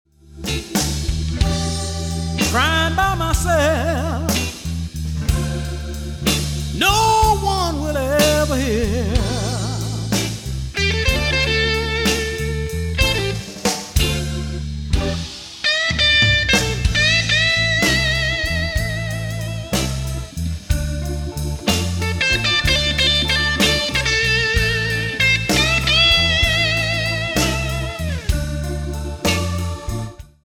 bass
drums
There's just no hurt in his voice.